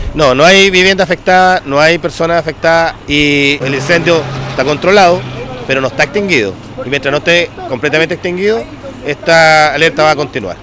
A su vez, el delegado Presidencial, Jorge Alvial, explicó que el incendio no se ha extinguido.